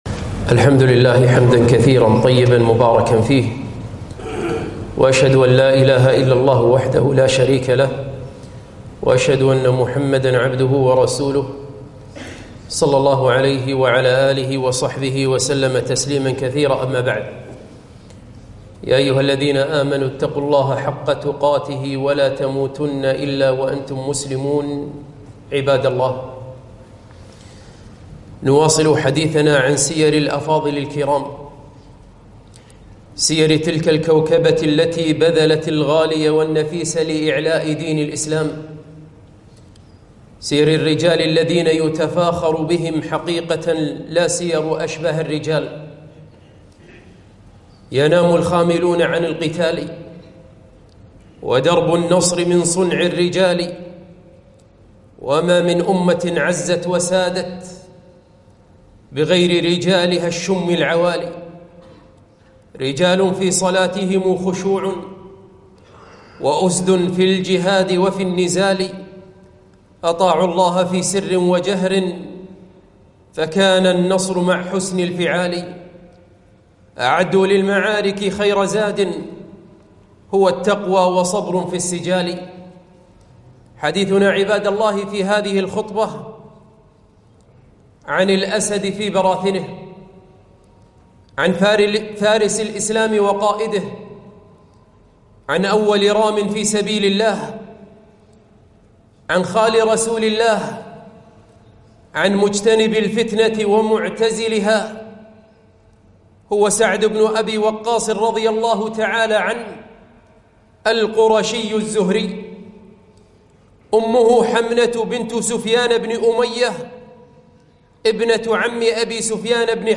خطبة - سعد بن أبي وقاص رضي الله عنه